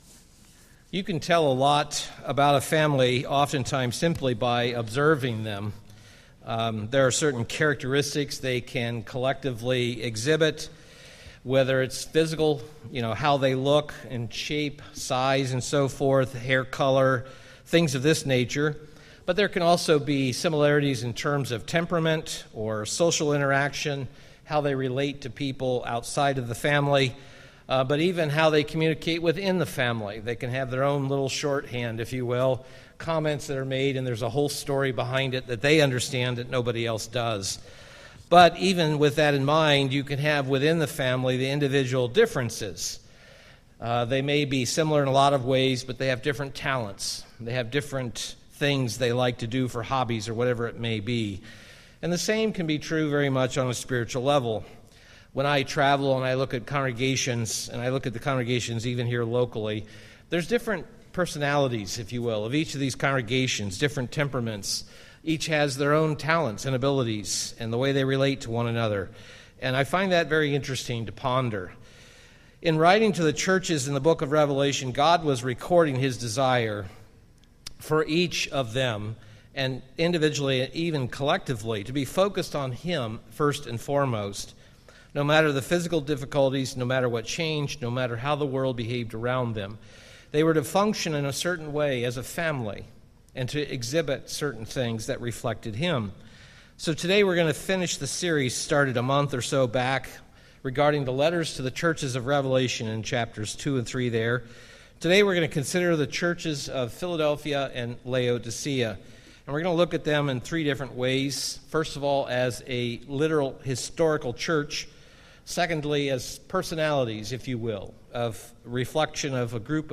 Given in Milwaukee, WI